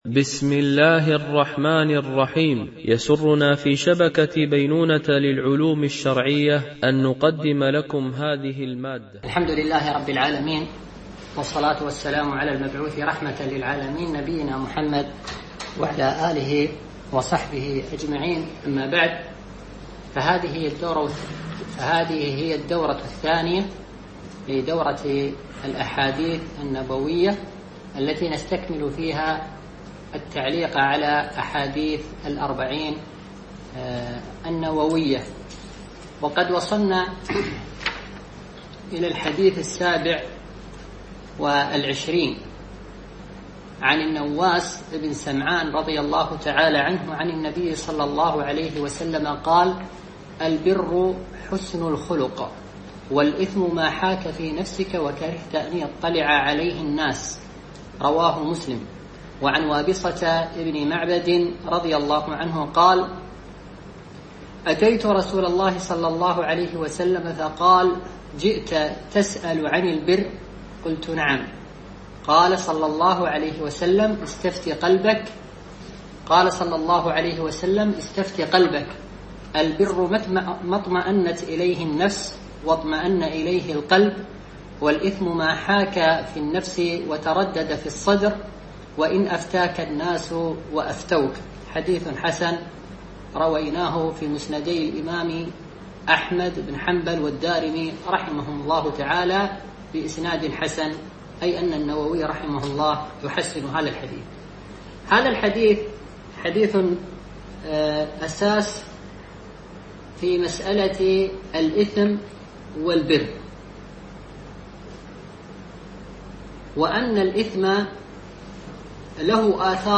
دورة علمية عن بعد